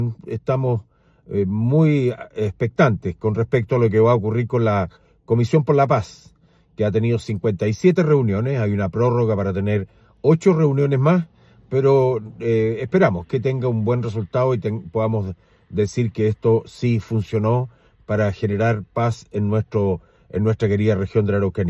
Mientras que el parlamentario de RN, Miguel Becker, mantiene la esperanza de llegar a buenos resultados.